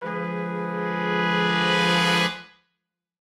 Index of /musicradar/gangster-sting-samples/Chord Hits/Horn Swells
GS_HornSwell-Ddim.wav